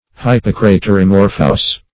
Search Result for " hypocraterimorphous" : The Collaborative International Dictionary of English v.0.48: Hypocraterimorphous \Hyp`o*cra*ter`i*mor"phous\, a. [Pref. hypo- + Gr. krath`r bowl + morfh` form.]